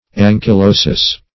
Ankylosis \An`ky*lo"sis\, n.